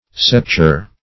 Septuor \Sep"tu*or\, n. [F.] (Mus.)